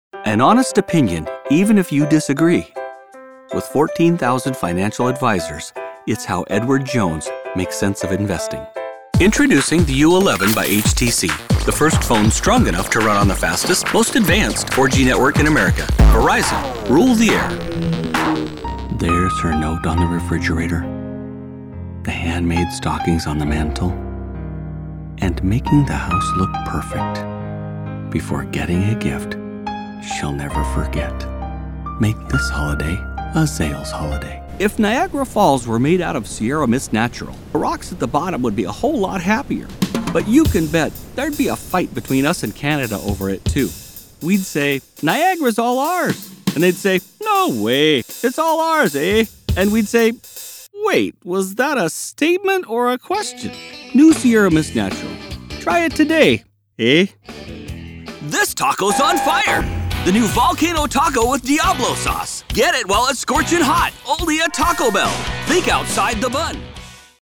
Browse professional voiceover demos.
1204COMMERCIAL_DEMO_MASTER.mp3